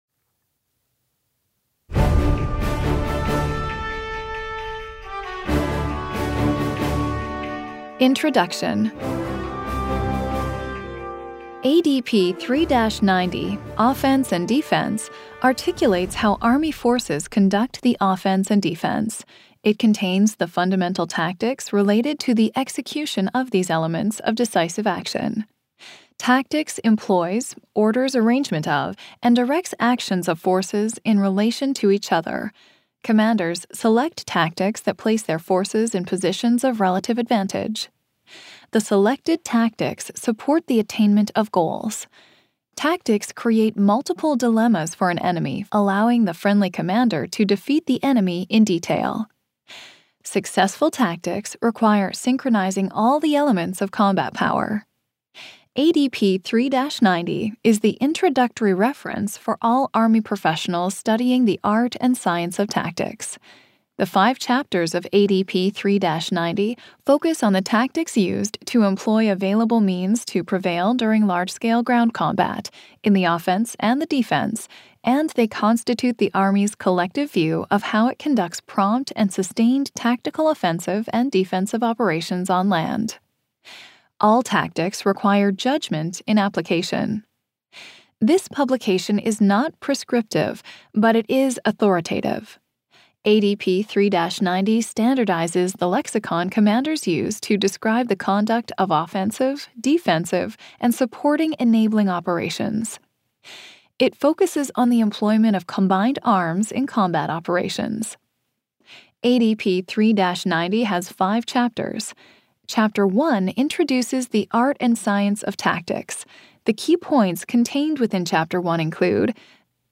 This is the download page for the Introduction of Army Doctrine Audiobook of Army Doctrine Publication (ADP) 3-90, Offense and Defense
It has been abridged to meet the requirements of the audiobook format.